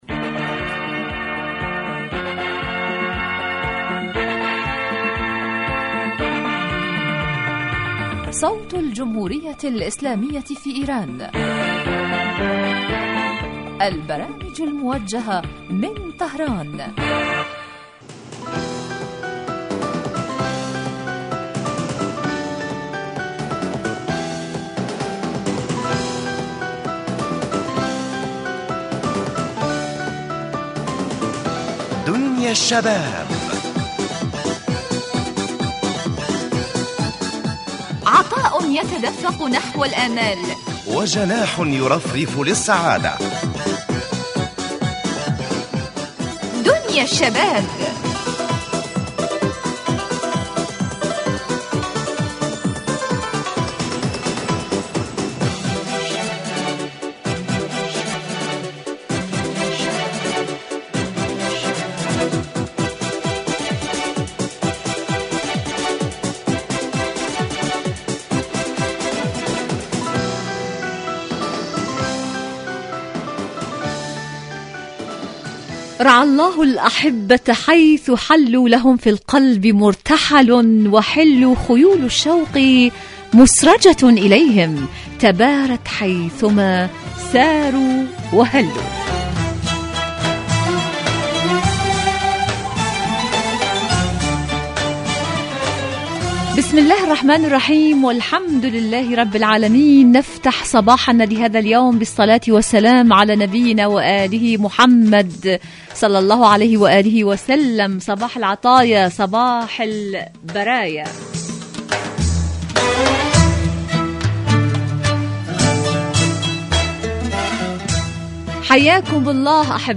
إذاعة طهران-دنيا الشباب: بحضور الضيفتين